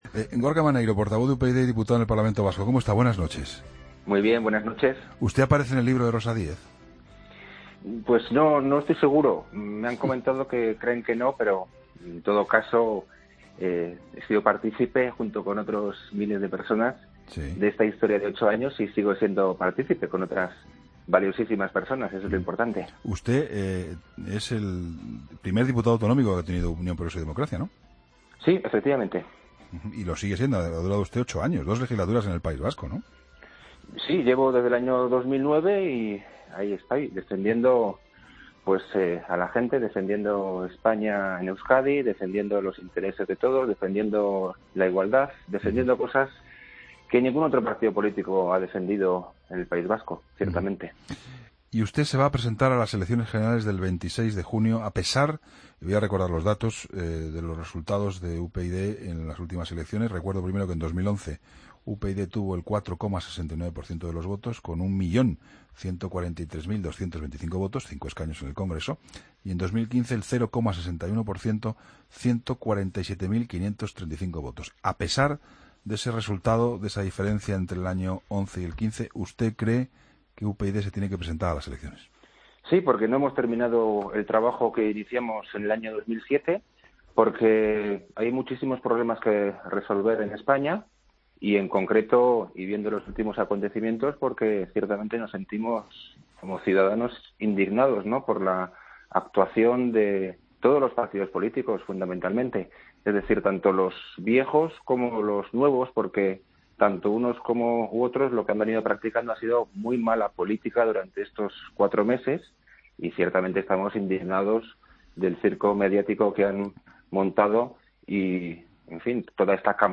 Entrevista a Gorka Maneiro, diputado vasco y portavoz de UPyD, en 'La Linterna'